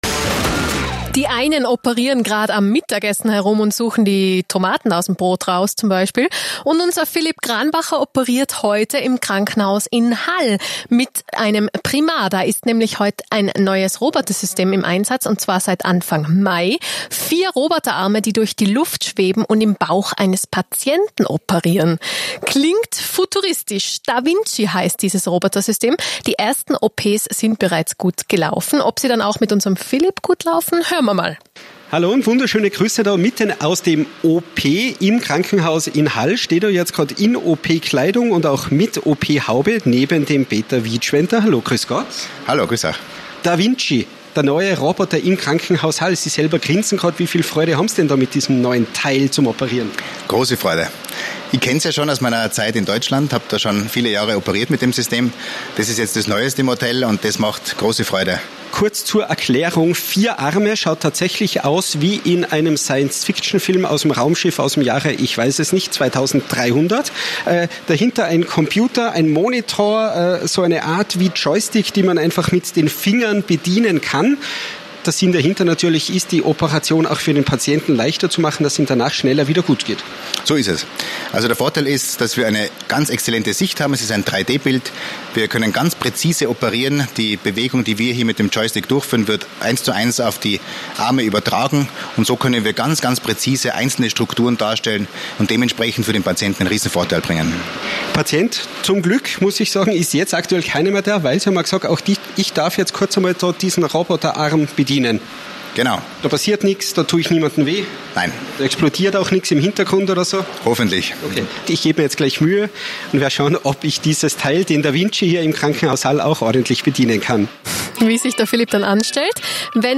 Auch Liferadio Tirol hat uns im LKH Hall besucht und einen Beitrag direkt aus dem OP gesendet.